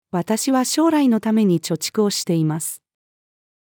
私は将来のために貯蓄をしています。-female.mp3